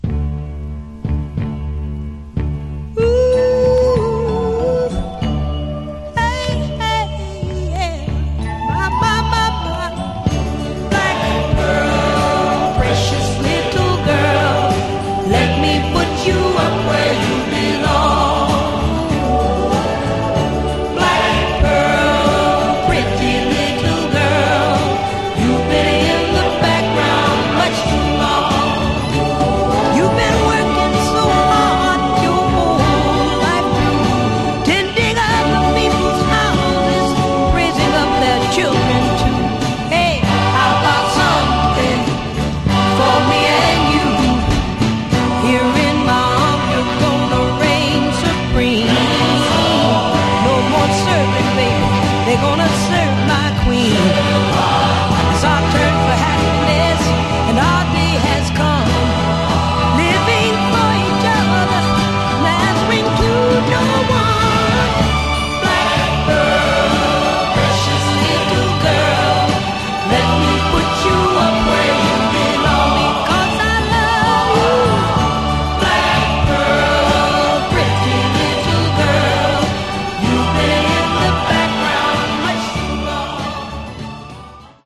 Genre: Other Northern Soul
This is a beautiful copy with pristine audio